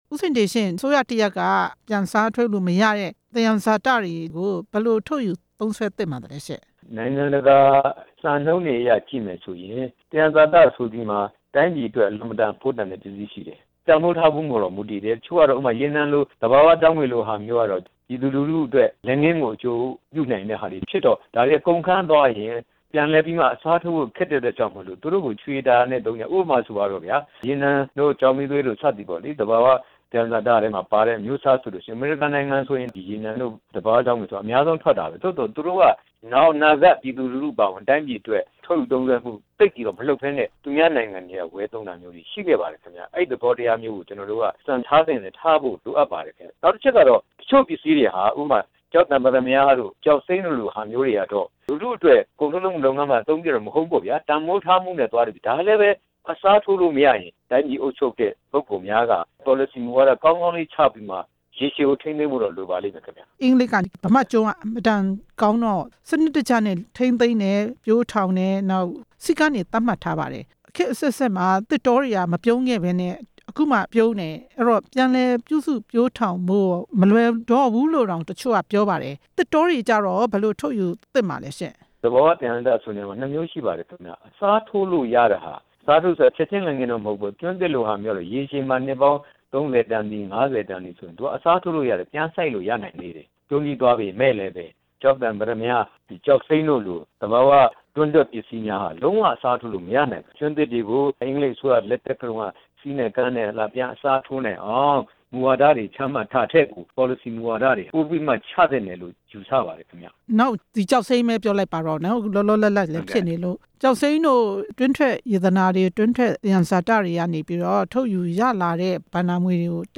စီးပွားရေးသုတေသီ
ဆက်သွယ်မေးမြန်းထားပါတယ်။